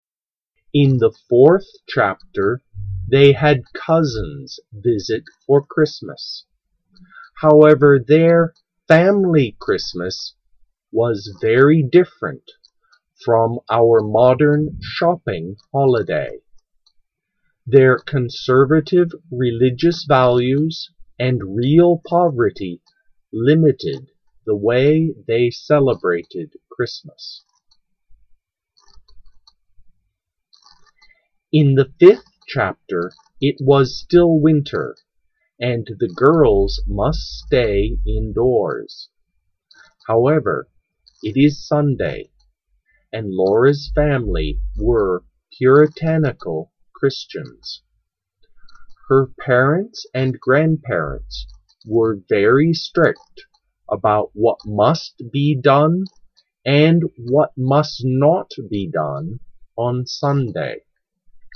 This summer I will read you the seventh chapter of The Little House in the Big Woods by Laura Ingalls Wilder. Of course it is slightly edited to make it easier to understand.